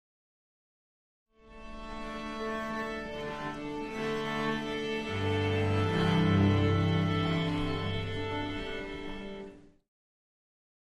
Orchestra
Orchestra Tuning Ambience 4 - Short Symphonic - Musical